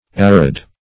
Arid \Ar"id\, a. [L. aridus, fr. arere to be dry: cf. F. aride.]